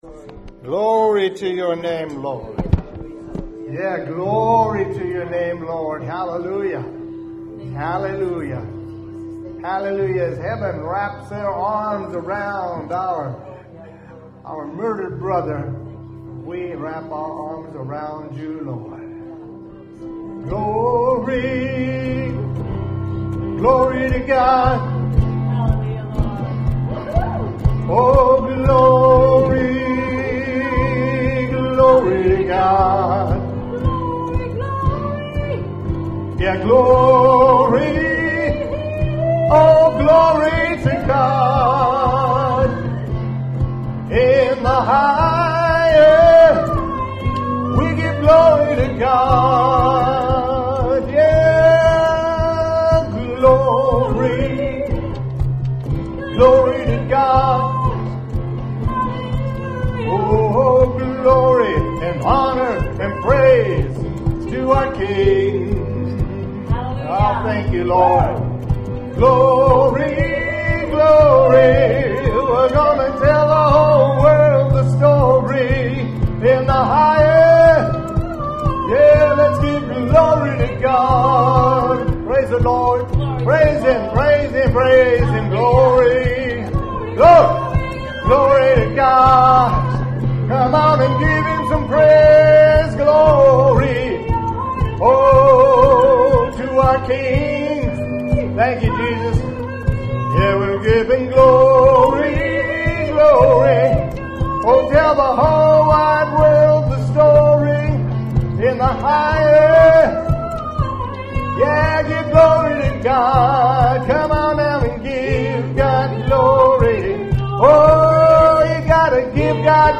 WORSHIP 914.mp3